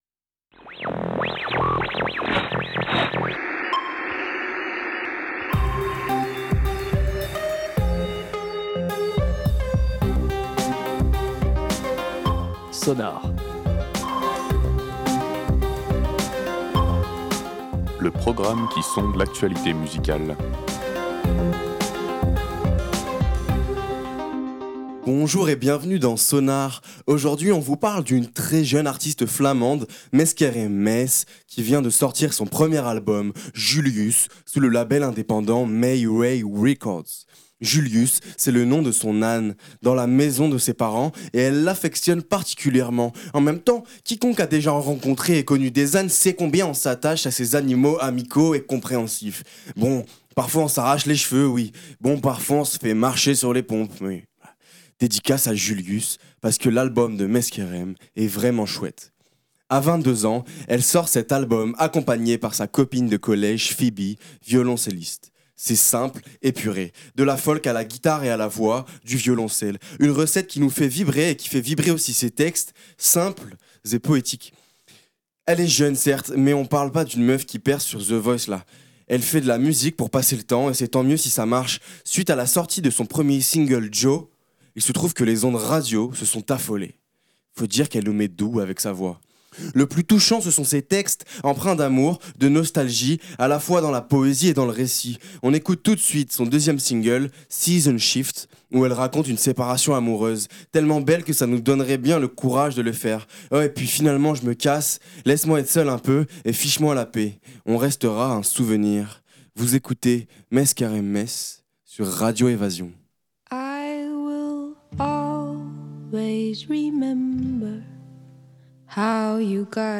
Réécoutez l'émission
Un album folk guitare voix prenant, avec quelques touches de violoncelle.
C’est simple, épuré. De la folk à la guitare et à la voix, du violoncelle.